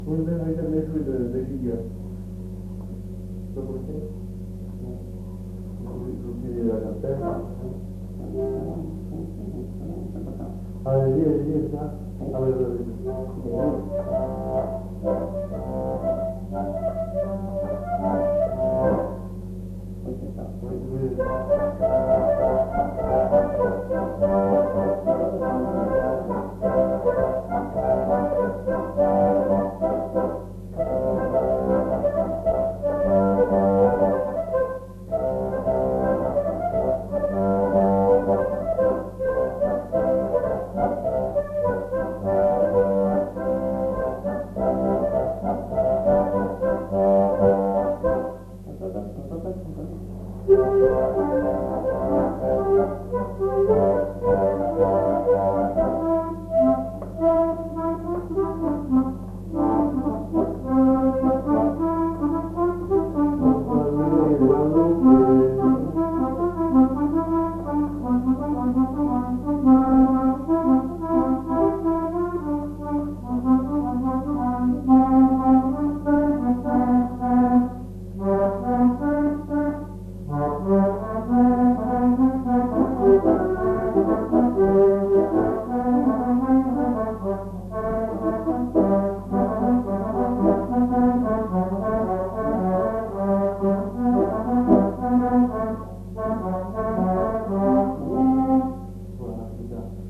Aire culturelle : Marmandais gascon
Lieu : Escassefort
Genre : morceau instrumental
Instrument de musique : accordéon diatonique
Danse : gigue
Notes consultables : Est d'abord jouée par le collecteur pour permettre à l'interprète de se remémorer le morceau.